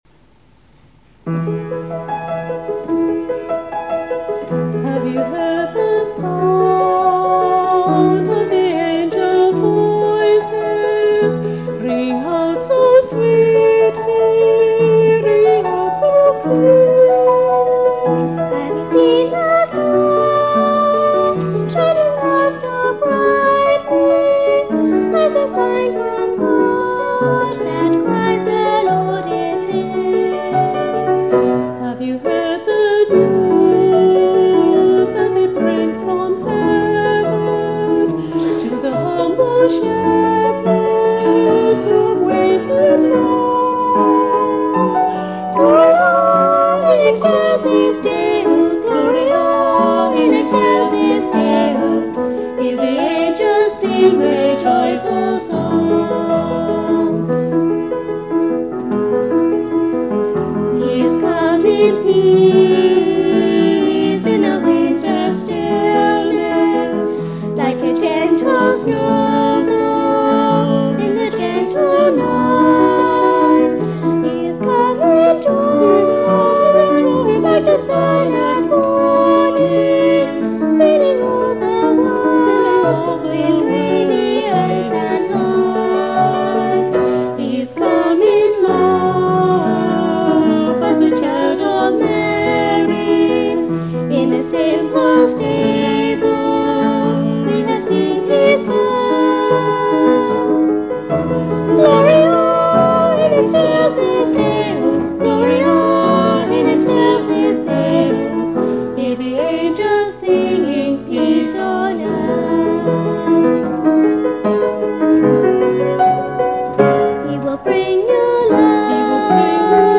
In December 2004 we experimented briefly in recording the choir at Trinity Bowdon, using a tape recorder.